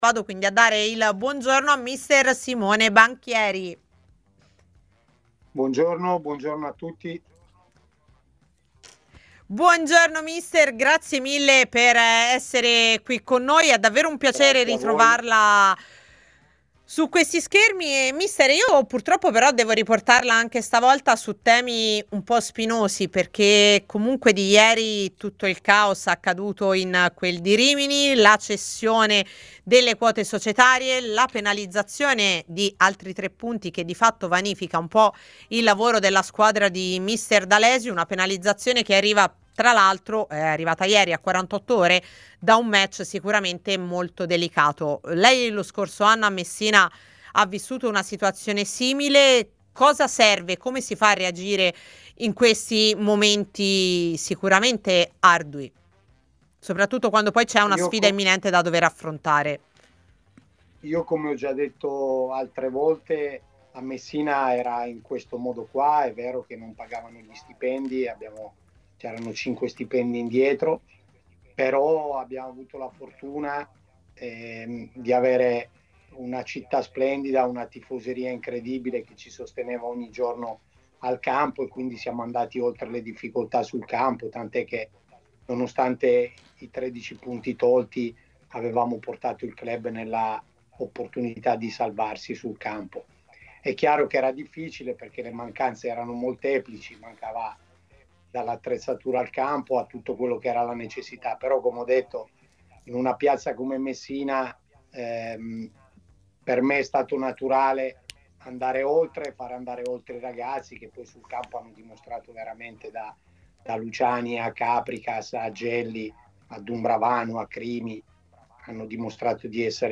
Ascolta l'audio Ospite di A Tutta C